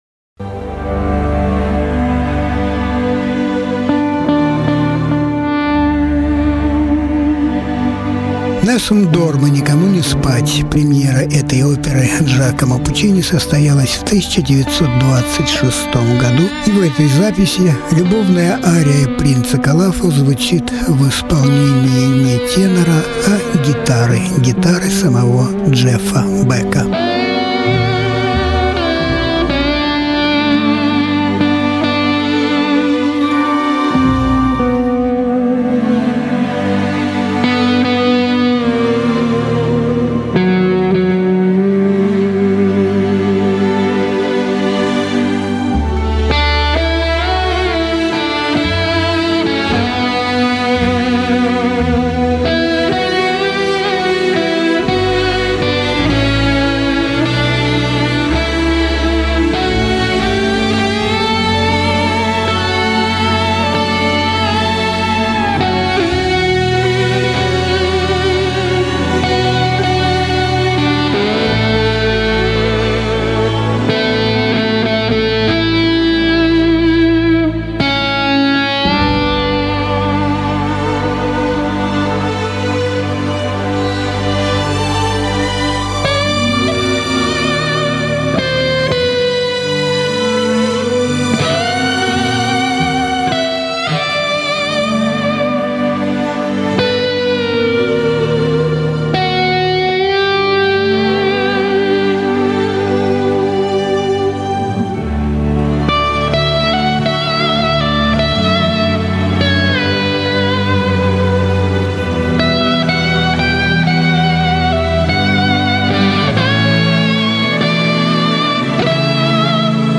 Жанр: Блюзы